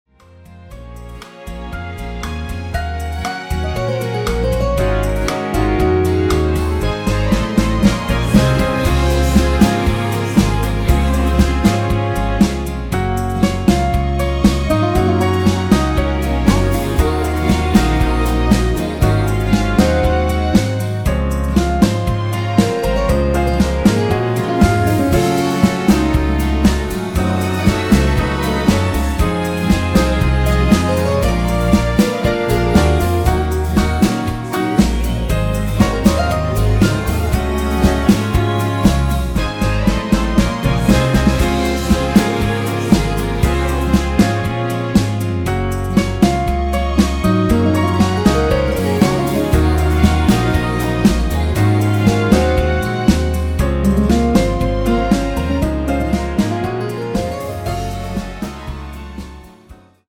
(+1) 올린 코러스포함된 MR 입니다.(미리듣기 참조).
Db
◈ 곡명 옆 (-1)은 반음 내림, (+1)은 반음 올림 입니다.
앞부분30초, 뒷부분30초씩 편집해서 올려 드리고 있습니다.
중간에 음이 끈어지고 다시 나오는 이유는